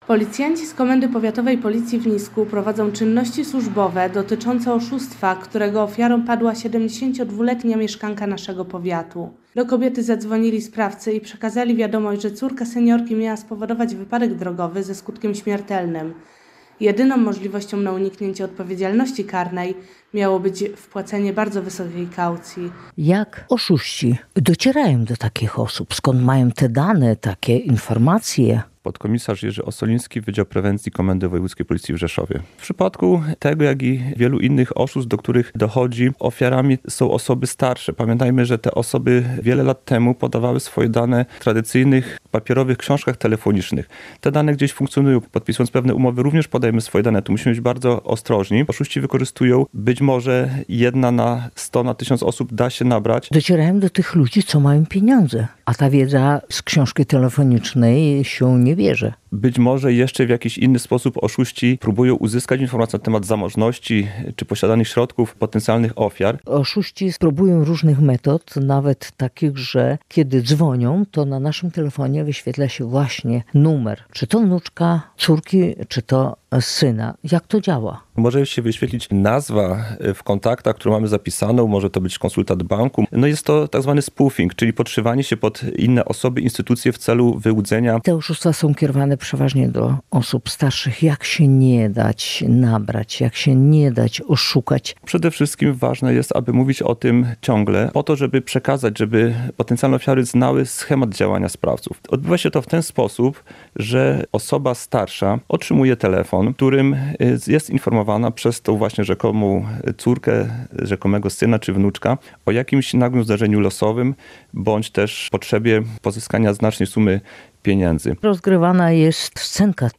Uwierzyła w historię o wypadku • Relacje reporterskie • Polskie Radio Rzeszów